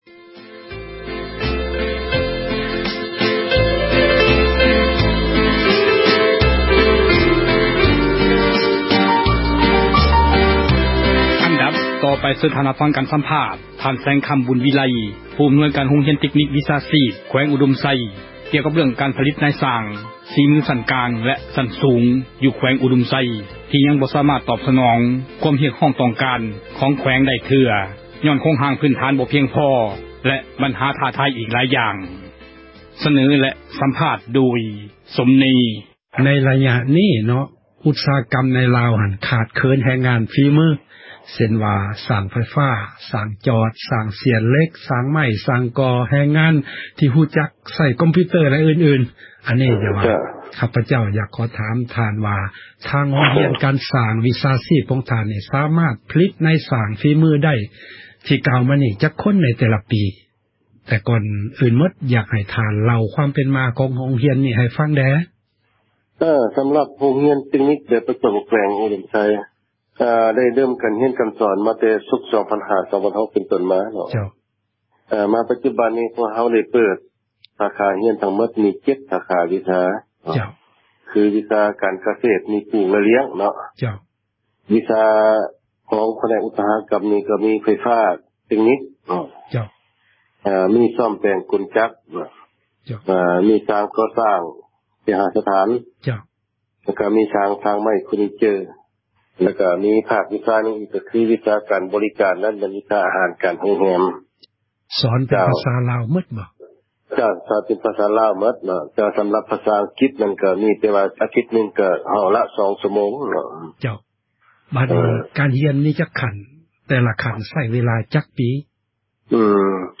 ການ ສຳພາດ